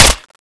fire.wav